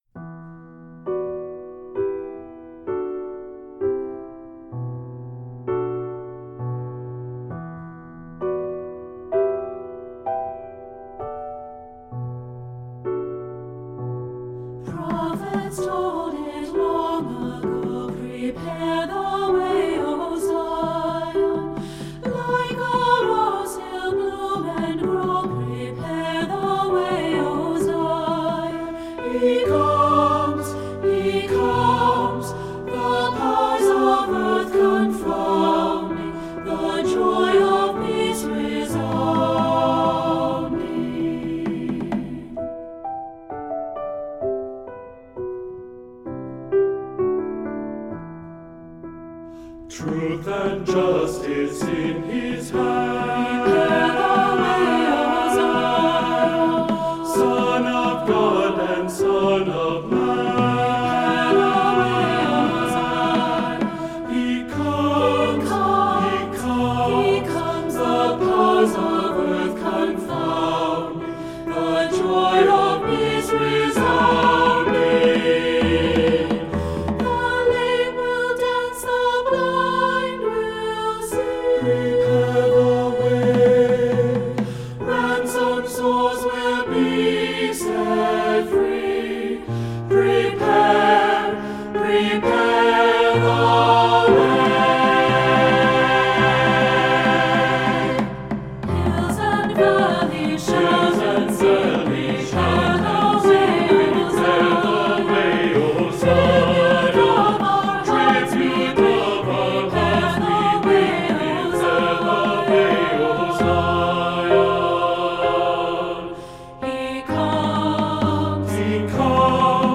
Voicing: 2-Part Mixed and optional percussion